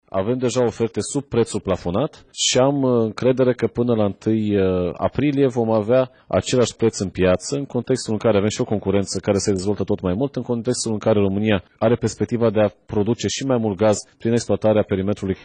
Ministrul Energiei, Bogdan Ivan: „Avem deja oferte sub prețul plafonat și am încredere că, până la 1 aprilie, vom avea același preț în piață”
14ian-21-Bogdan-Ivan-despre-pretul-gazelor-naturale.mp3